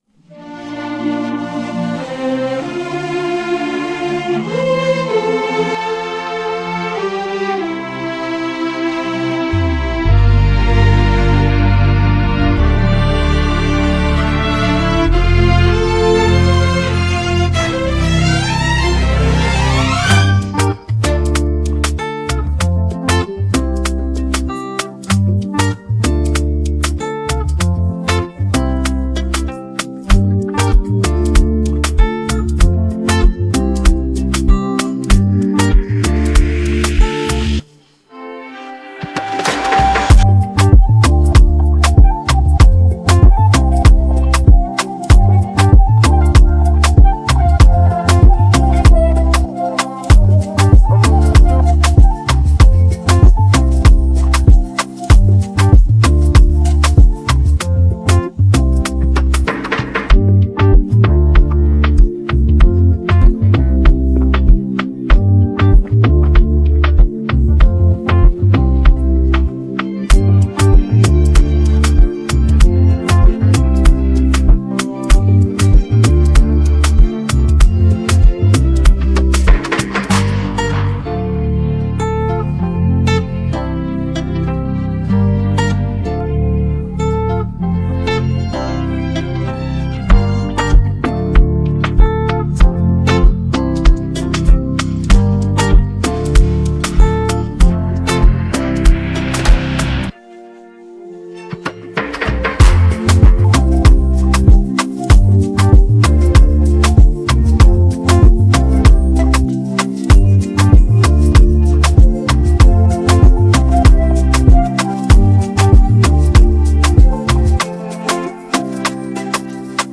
Afrobeat instrumental 2026 Download